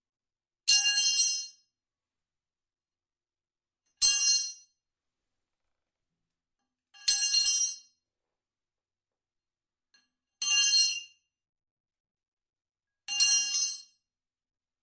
钢管落在混凝土上 " 42 扳手落在混凝土地面上，室内。5次撞击
描述：将42扳手放在水泥地面上.RAW文件记录器：放大H6，带XY capsuelResolution：96/24
Tag: 拟音 铁匠 扳手 混凝土 金属 金属 地板 命中 冲击